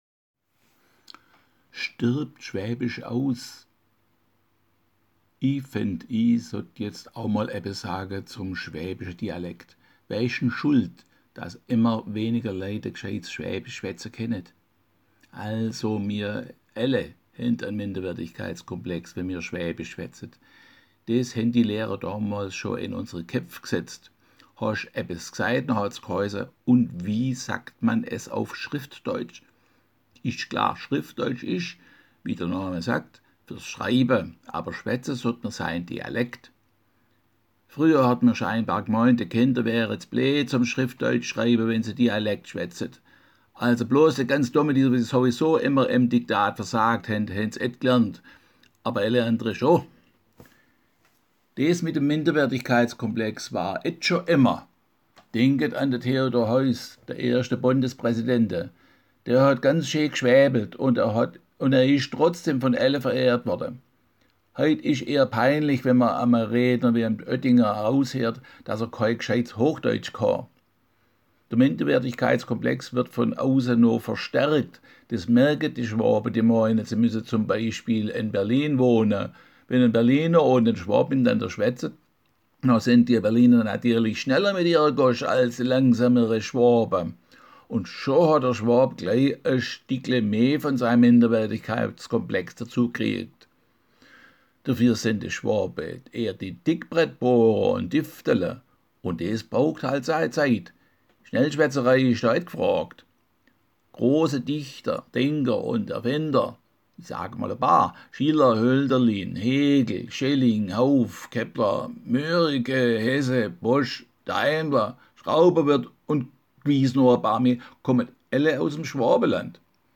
Schdirbd Schwäbisch aus?
Schdirbd-Schwaebisch.mp3